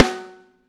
high snare ff.wav